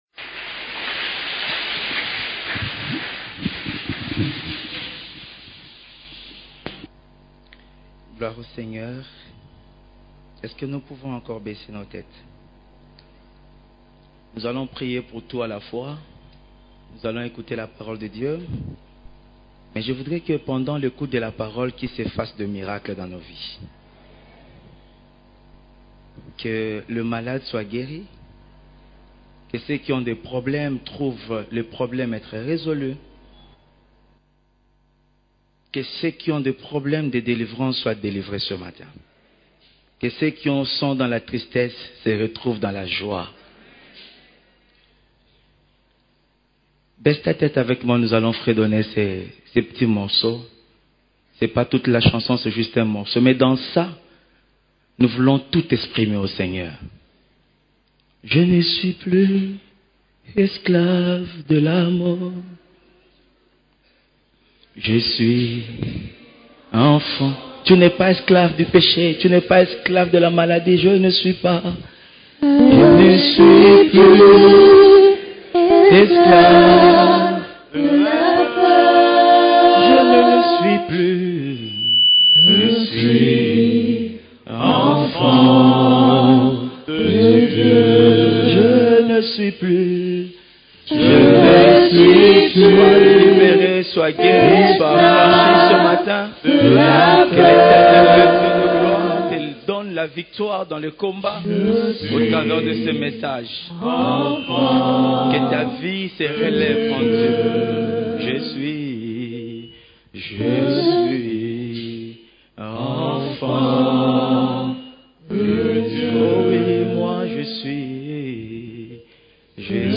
CEF la Borne, Culte du Dimanche